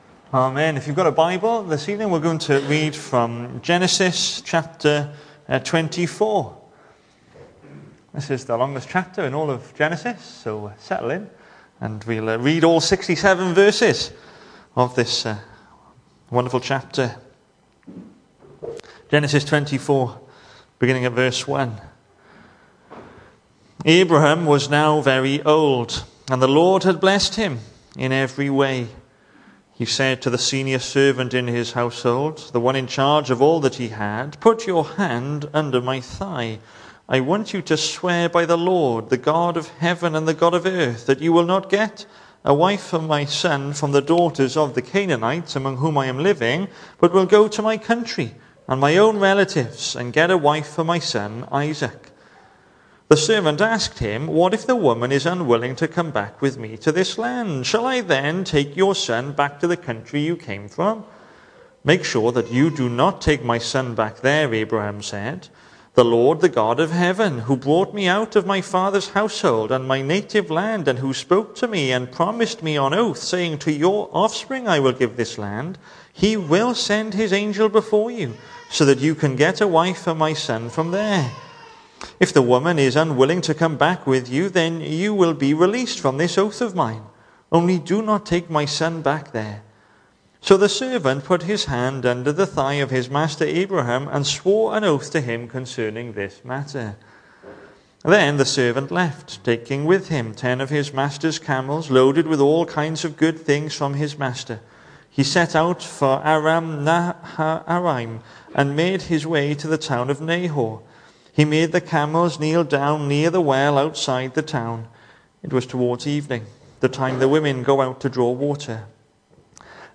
The 15th of March saw us hold our evening service from the building, with a livestream available via Facebook.